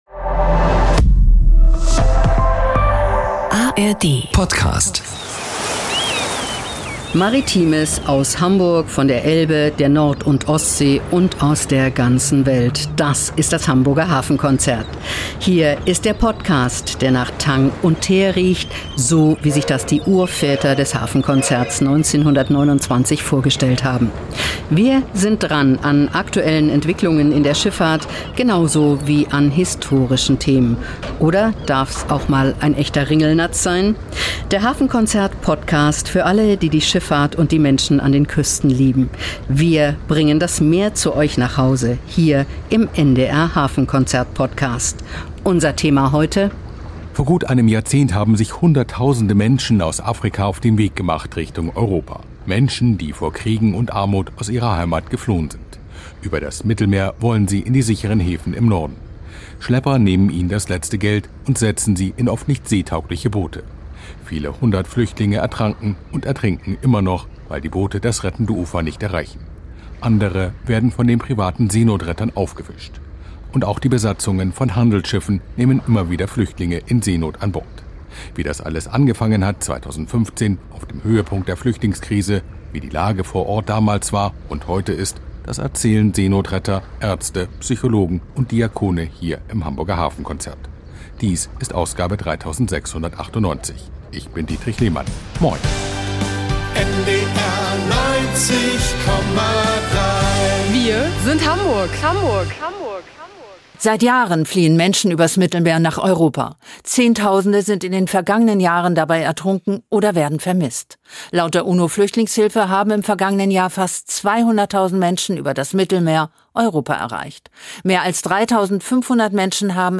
Das erzählen Seenotretter, Ärzte, Psychologen und Diakone. Es geht um Rettungseinsätze, politische Auseinandersetzungen, Traumata und Hoffnungen – und die Frage: Welche Verantwortung trägt Europa an dieser Situation?